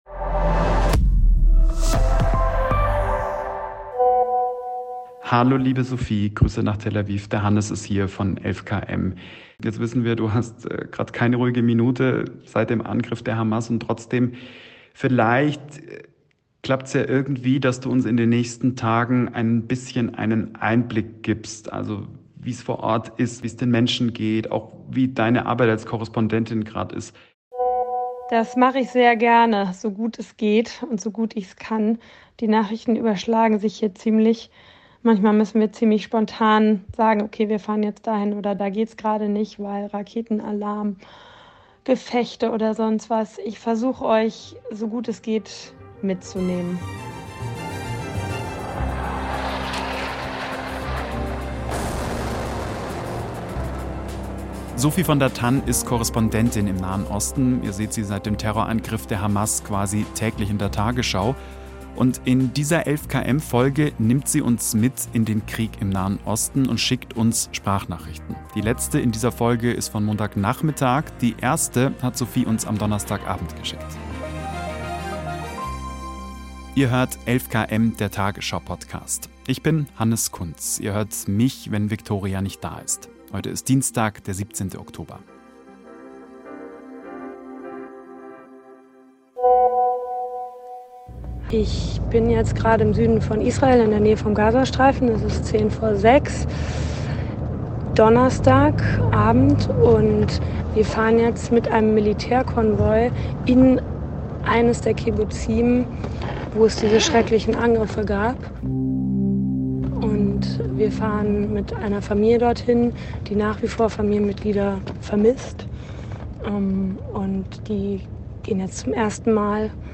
Israel: Sprachnachrichten aus dem Krieg ~ 11KM: der tagesschau-Podcast Podcast
Die letzte Sprachnachricht für diese Folge kam am späten Nachmittag, des 16. Oktober 2023.